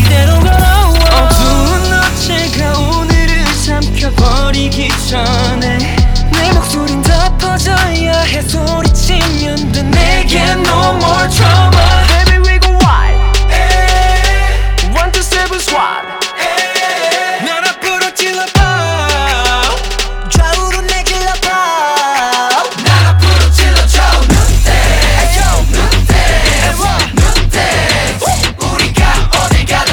• K-Pop